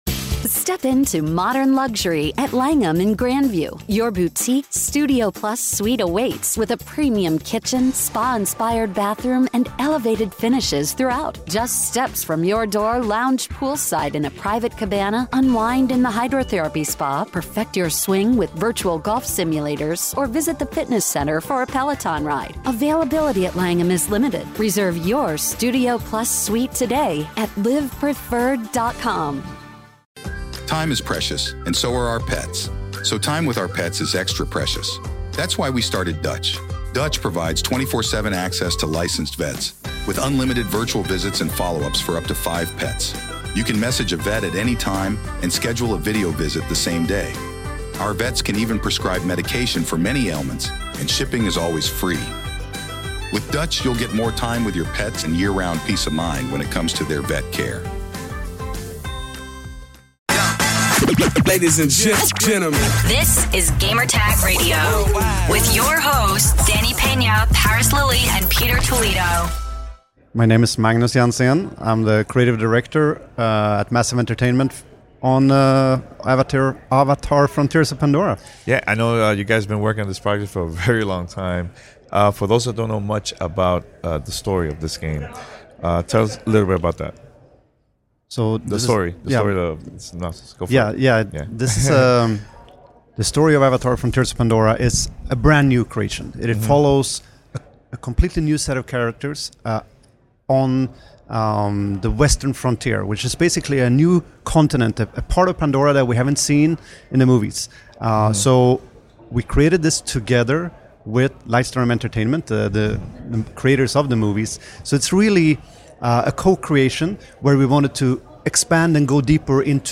Avatar: Frontiers of Pandora Interview